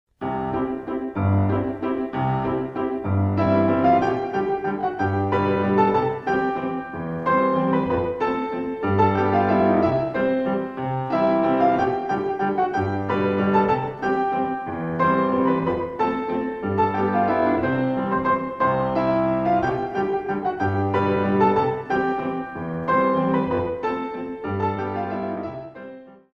64 Counts